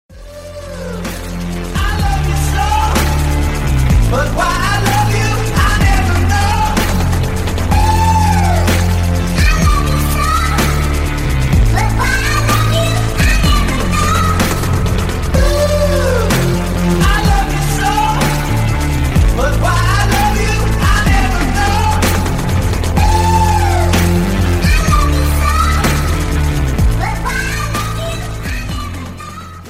• Качество: 128, Stereo
мужской вокал